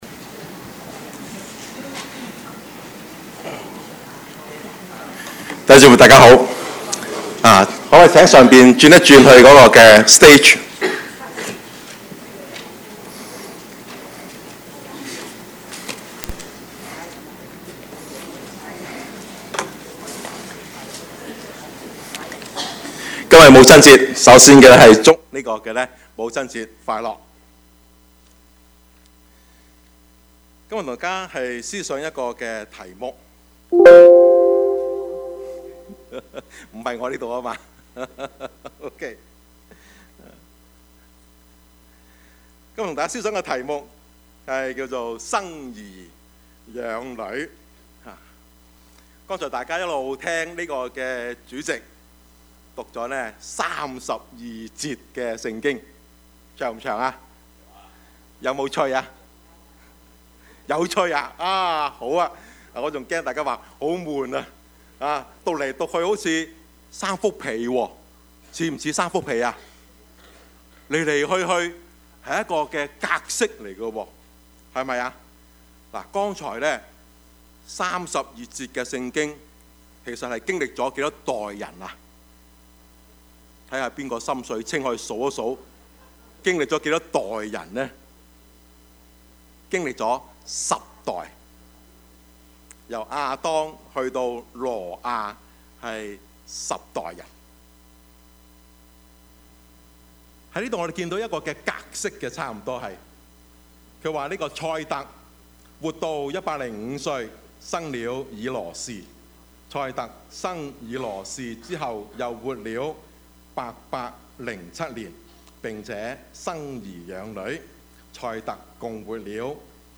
Service Type: 主日崇拜
Topics: 主日證道 « 慈禧與義和團 (二) 感恩與忘恩 »